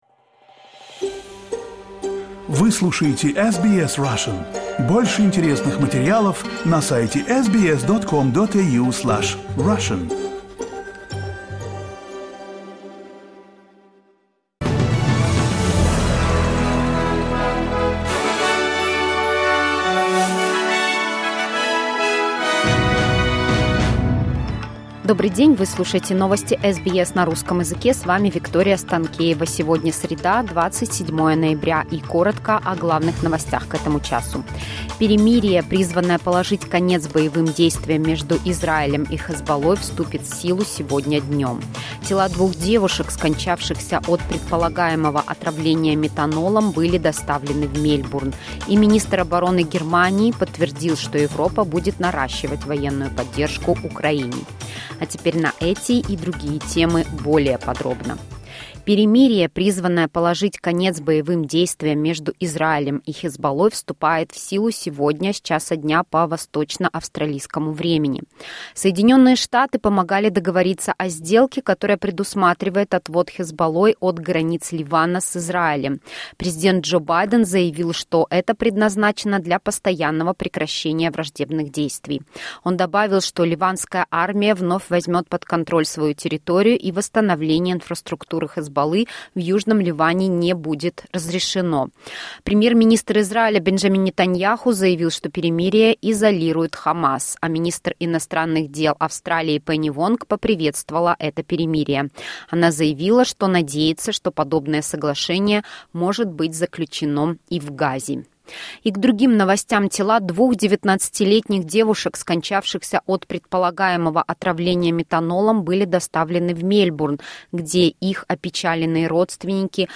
Новости SBS на русском языке — 27.11.2024